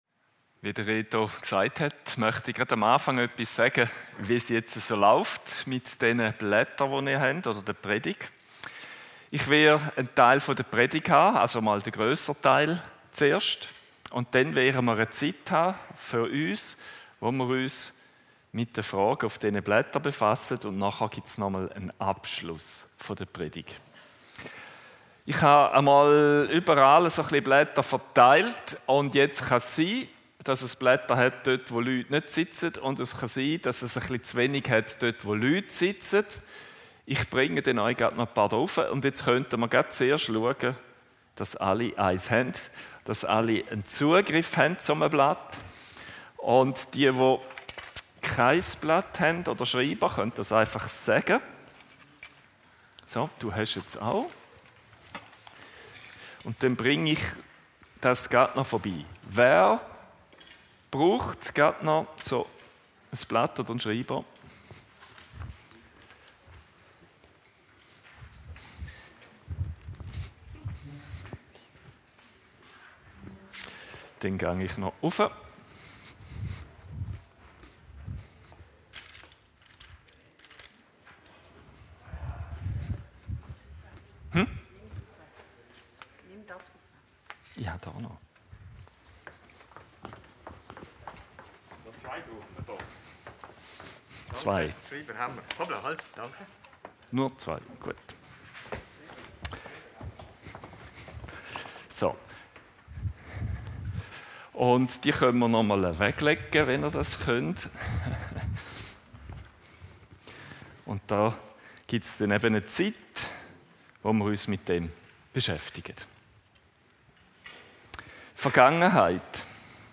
Aus der Themenreihe "Loslassen und Versöhnen" geht es in dieser Preidgt um die Vergangenheit.